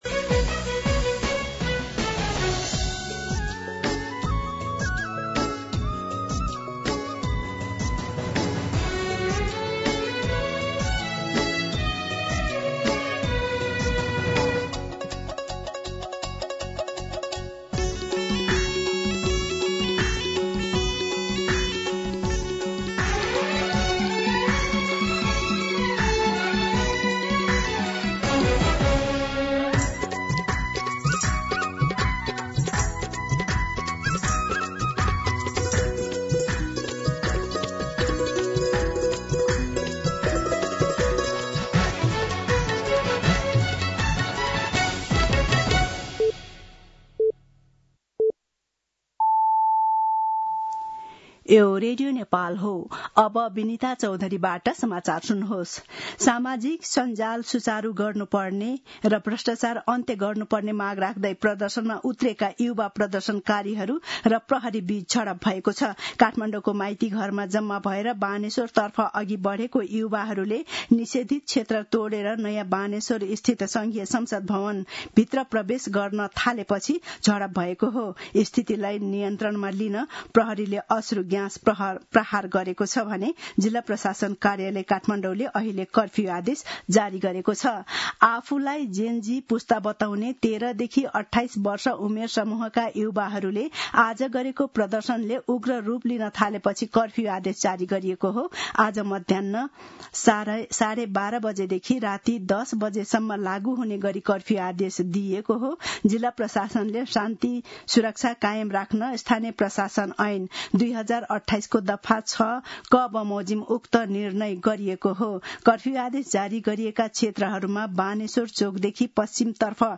दिउँसो १ बजेको नेपाली समाचार : २३ भदौ , २०८२
1-pm-News-2.mp3